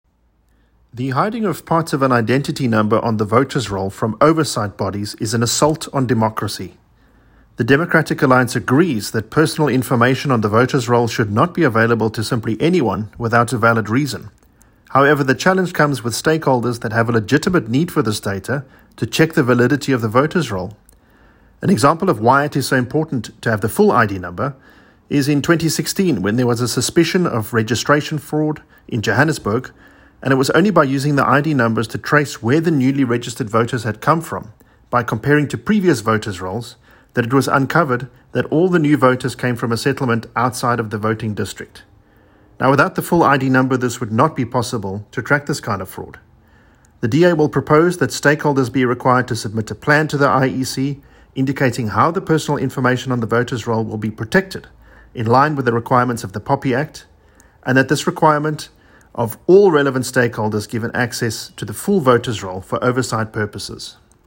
Please find attached a soundbite in
Adrian-English-Soudbite.mp3